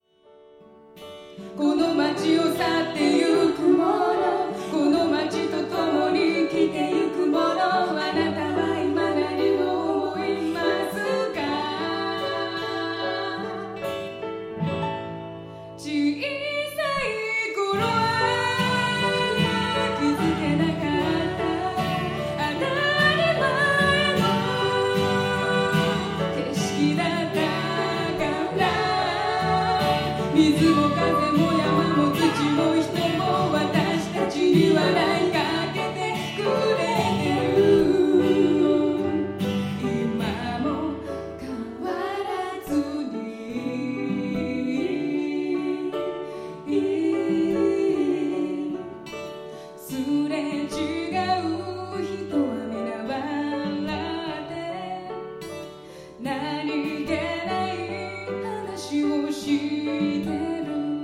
ピアノ
ギター
コカリナ（笛）
まず演奏に聞きほれました、衣装もよかった。
歌もギターも上手いので心に染み入ってくる。オカリナも効果的。
琉球旋法の発展形のような民謡風で音は少ないがリズムがどんどん進む独特の魅力、闊達さがいい。
音楽もコードに９やsus4を使った独特な世界を醸し出していて素敵ですね。